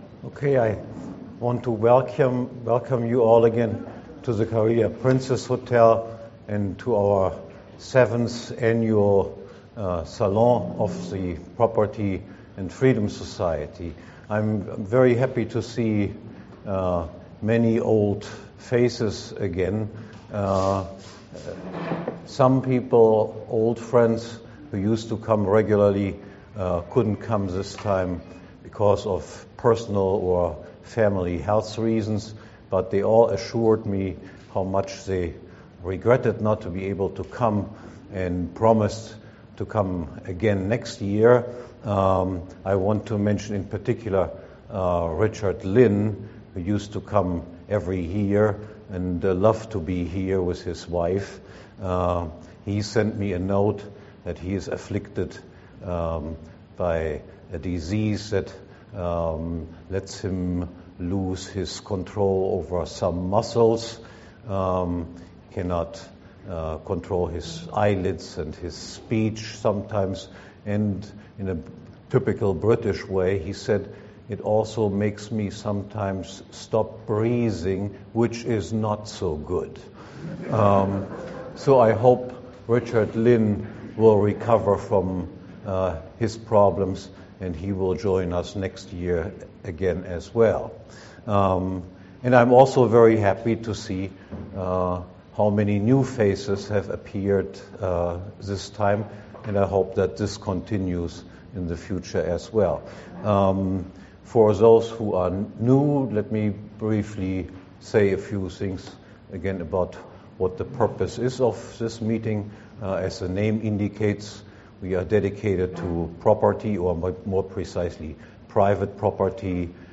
This lecture is from the 2012 meeting of the Property and Freedom Society.